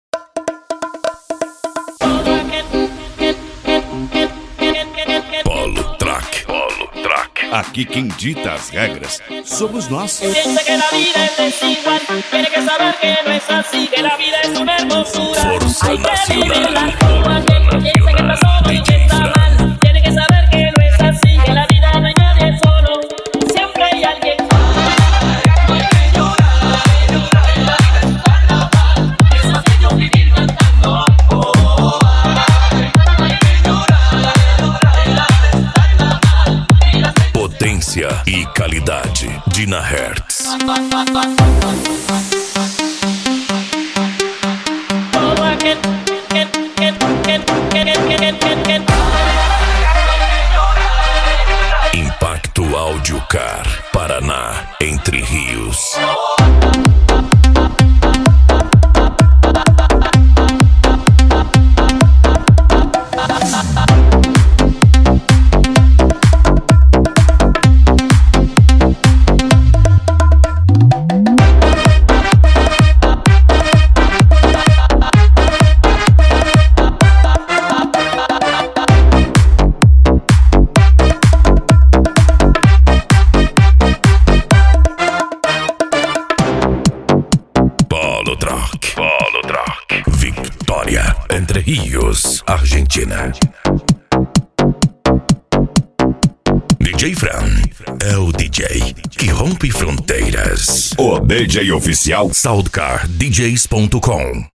Electro House
Eletronica
Remix